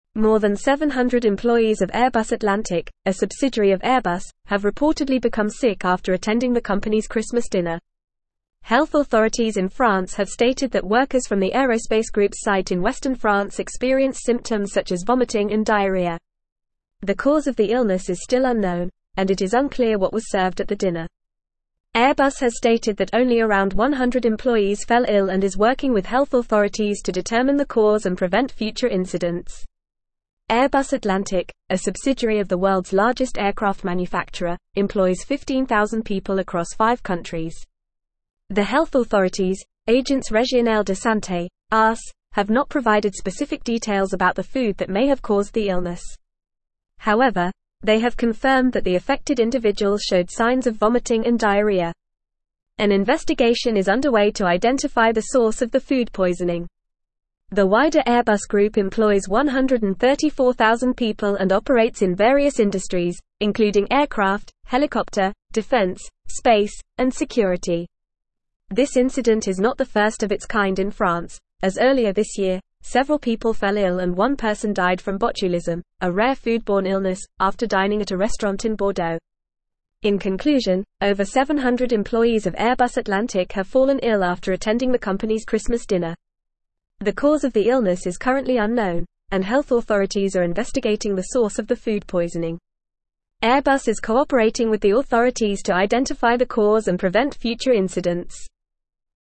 Fast
English-Newsroom-Advanced-FAST-Reading-Over-700-Airbus-staff-fall-ill-after-Christmas-dinner.mp3